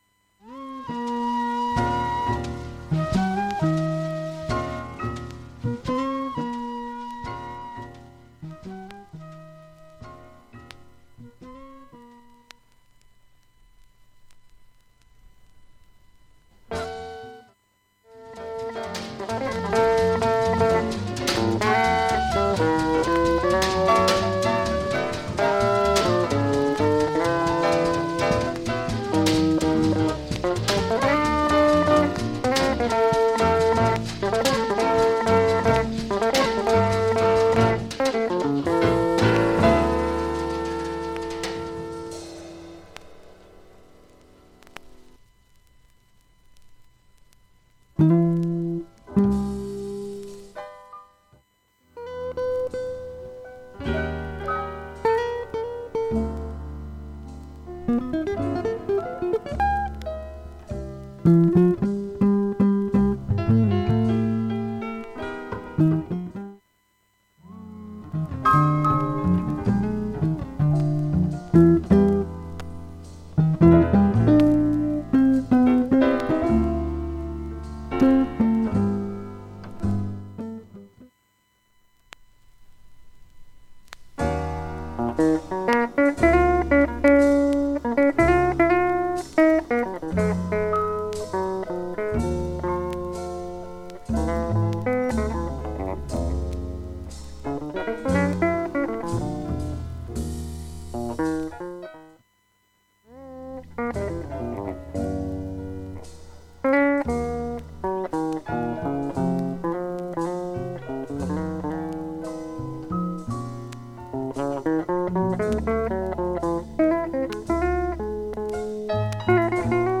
現物の試聴（上記録音時間3分）できます。音質目安にどうぞ